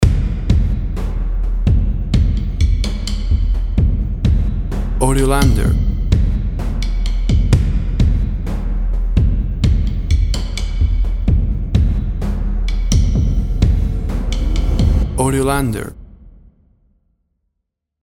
Great to build tension for reality based shows.
Tempo (BPM) 127